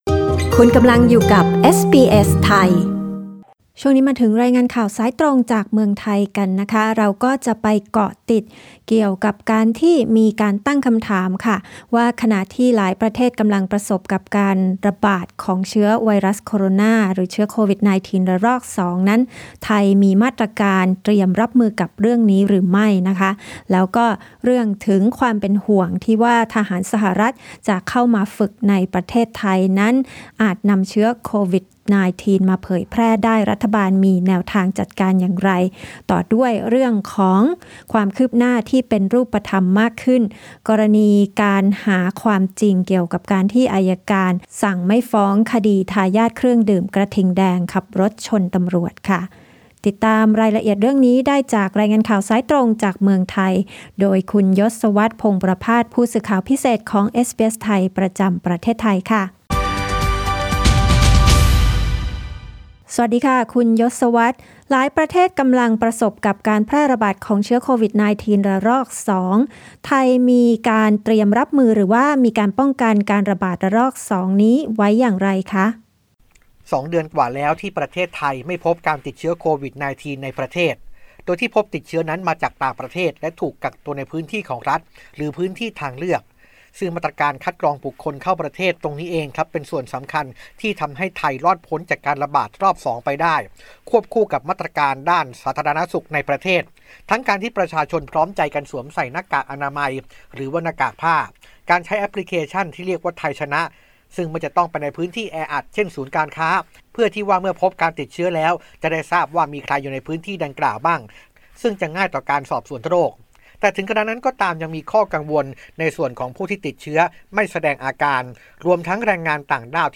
รายงานข่าวสายตรงจากเมืองไทย จากเอสบีเอส ไทย Source: Pixabay
thai_news_report_aug_6_podcast.mp3